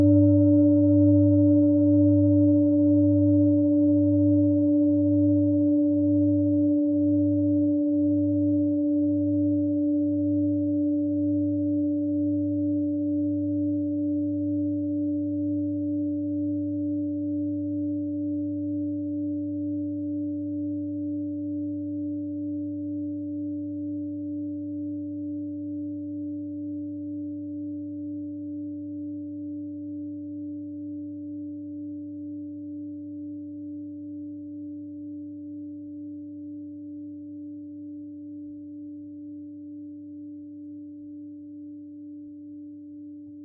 Klangschalen-Typ: Bengalen und Tibet
Klangschale 2 im Set 4
Durchmesser = 29,2cm
(Aufgenommen mit dem Filzklöppel/Gummischlegel)
klangschale-set-4-2.wav